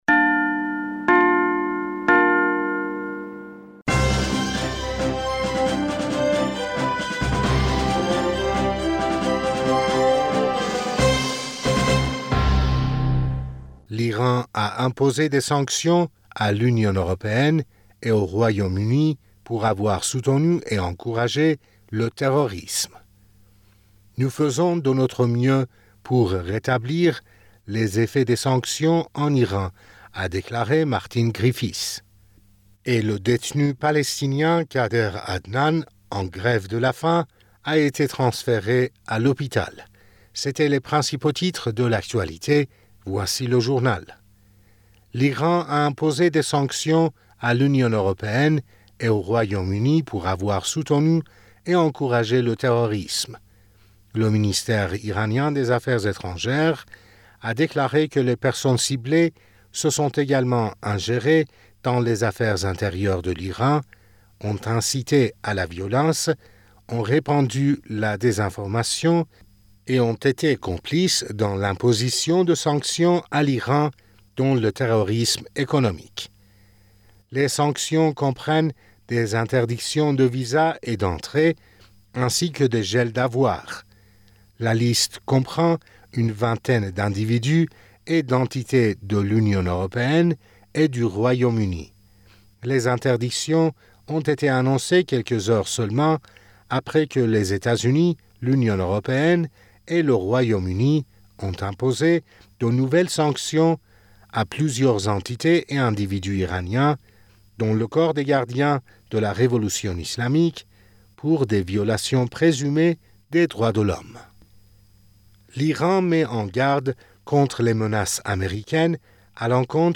Bulletin d'information du 25 Avril 2023